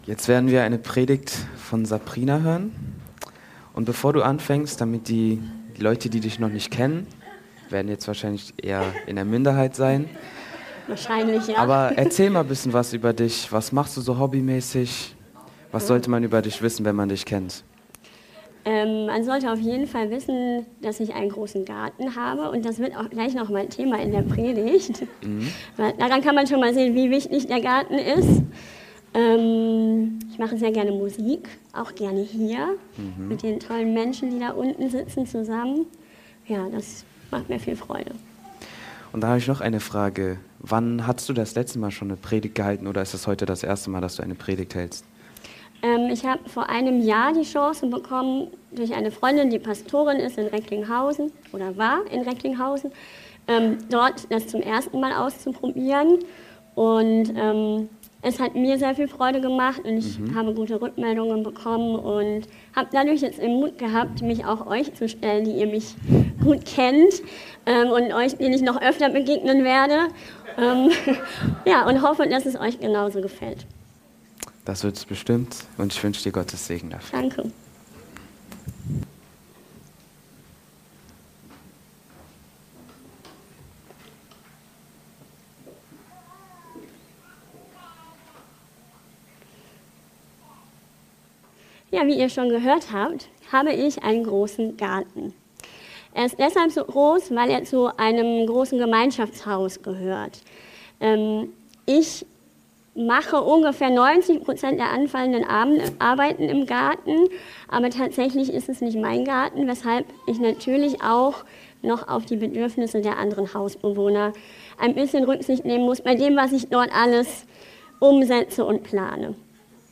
Predigt-Podcast